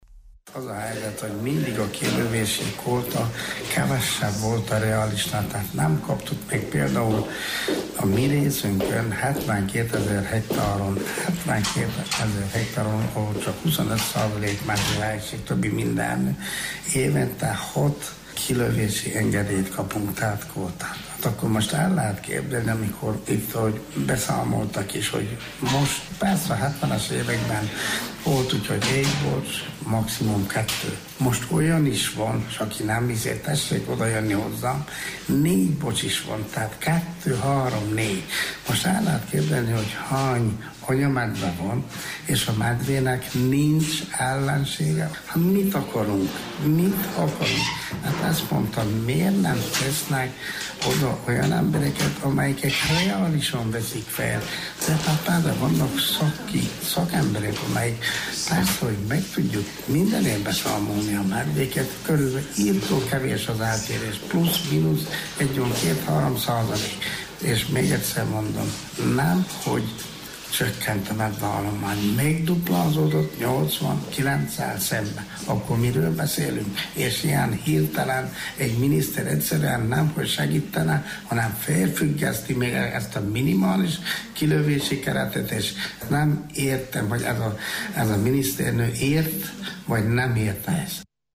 Sepsiszentgyörgyön, az országban elsőként megszervezett, teljes megyét érintő konzultáción a vidéki polgármesterek elégedetlenségüknek adtak hangot. Kiss József, Zágon polgármestere: